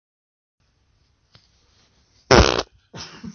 真实的屁 " 屁3
描述：真屁
Tag: 现实 放屁 真正